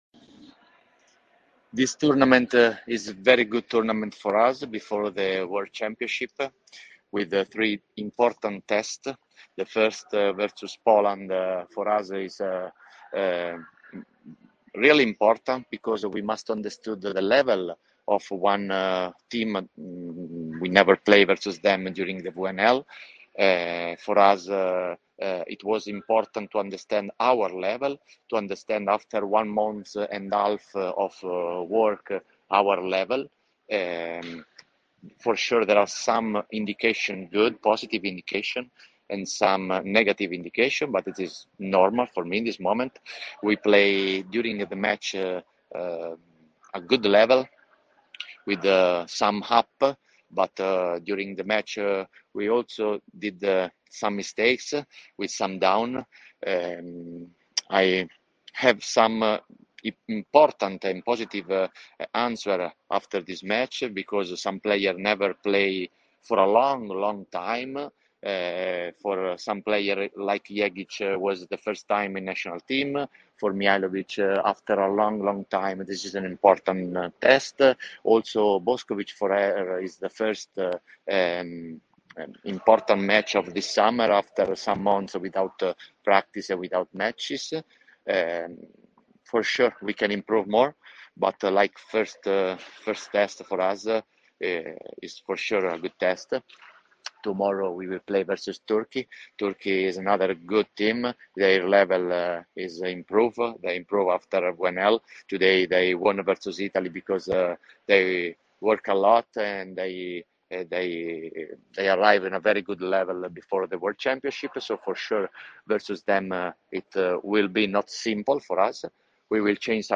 Izjava Danijelea Santarelija